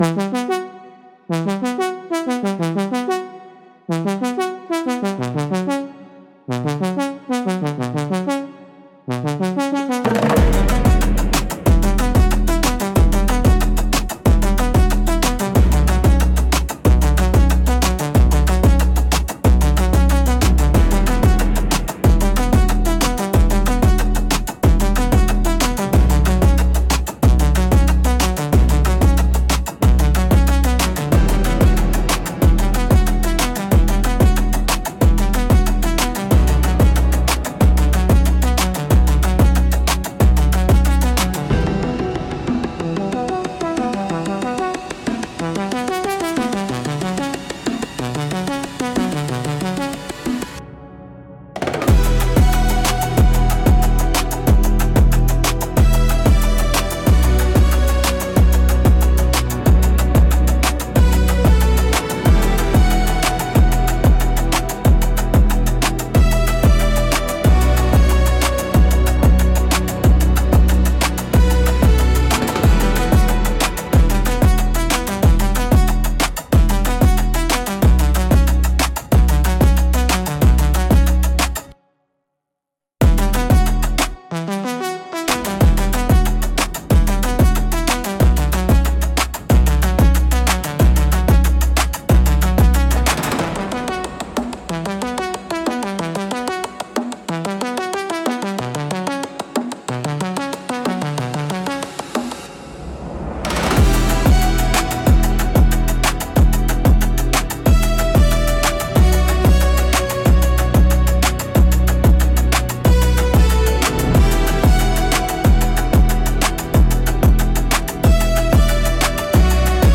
• Defined the audio direction: dark synthwave with cinematic trailer build-ups, heavy bass, and electronic accents.
• Selected a version with a rising synth intro, pulsing beats for action, and a final cinematic hit for the outro.
• Original Soundtrack: Suno AI–generated dark synthwave track.